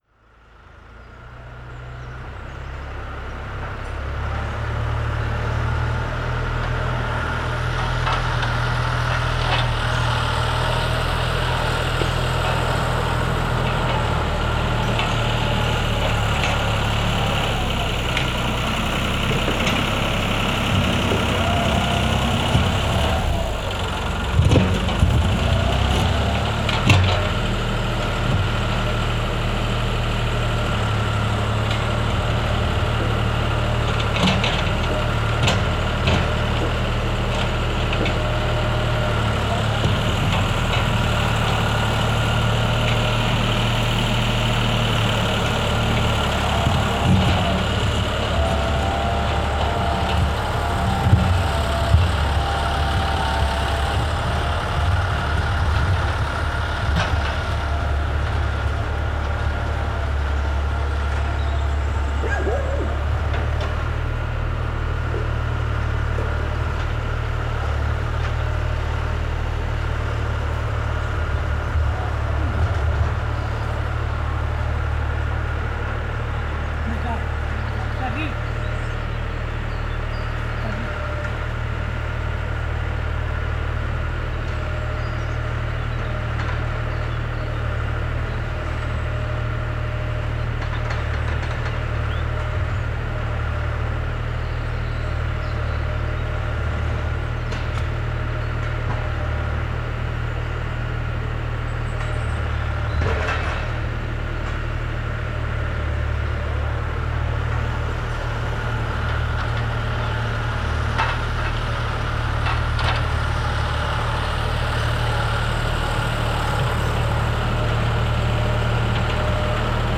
NODAR.00530 – Boa Aldeia, Farminhão e Torredeita: Terraplanagem de caminho junto ao pinhal à entrada poente de Boa Aldeia
Tipo de Registo: Som